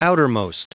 Prononciation du mot outermost en anglais (fichier audio)
Prononciation du mot : outermost